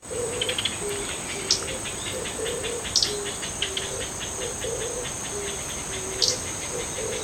記念に声を録音しました。
カエルの声をバックにキビタキとオオムシクイの地鳴きが交互に7秒間です。